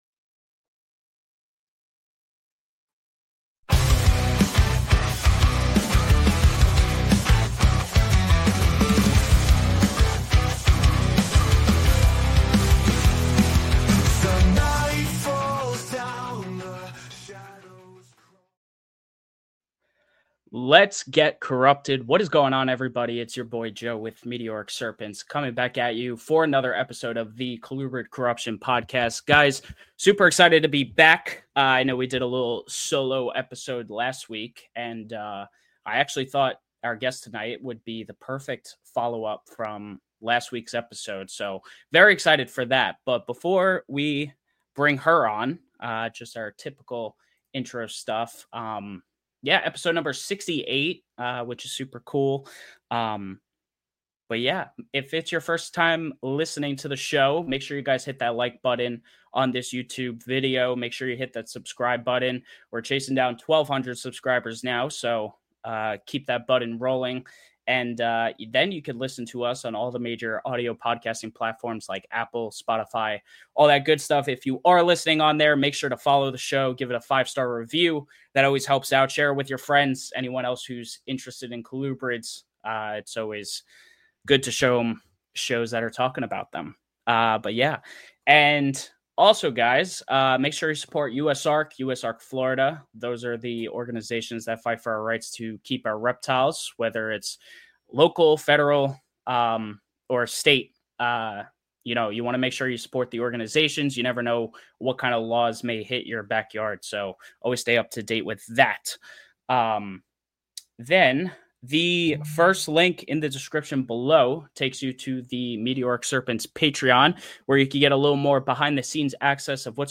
EPISODE 49 is a solo show where I will be giving a recap to 2024 both personally and with this podcast.